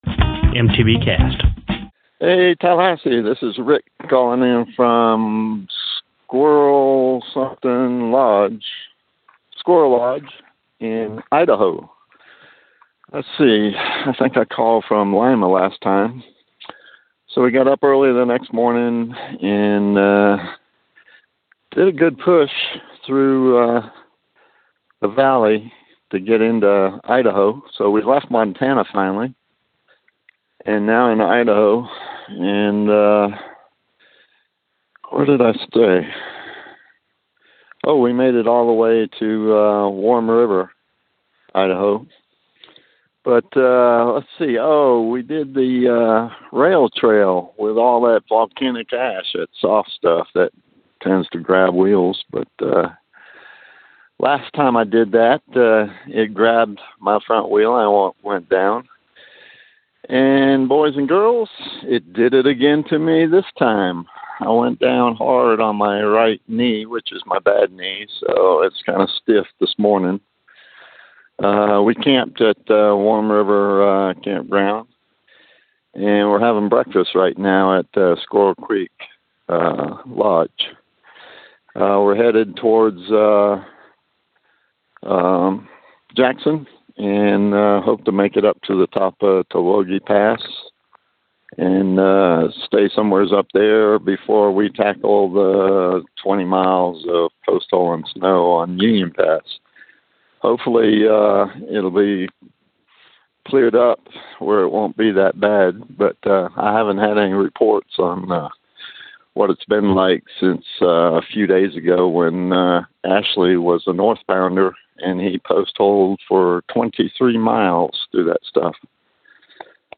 called in from Squirrel Creek Lodge
Posted in Calls , TD17 Tagged bikepacking , calls , cycling , MTBCast , TD17 , ultrasport permalink